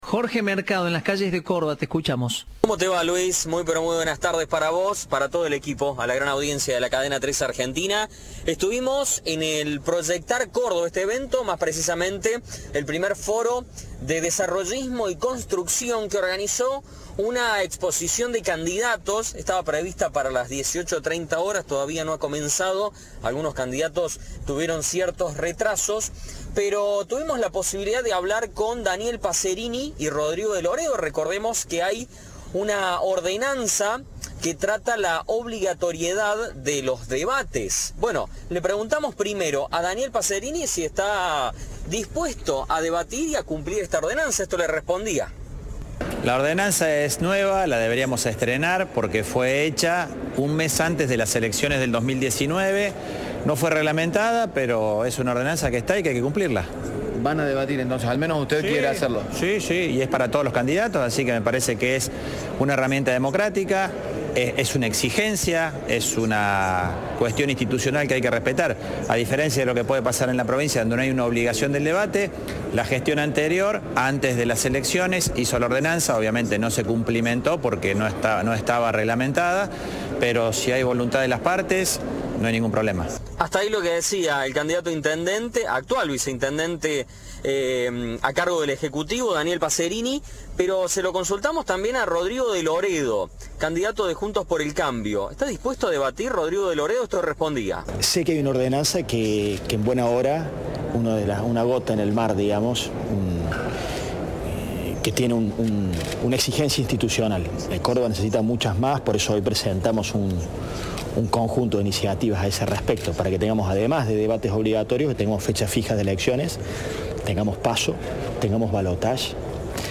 En diálogo con Cadena 3, los candidatos a intendente de Córdoba del PJ y JxC prometieron cumplir una ordenanza, que obliga a los aspirantes a gobernar la capital provincial a discutir sus ideas de cara a la sociedad.
Informe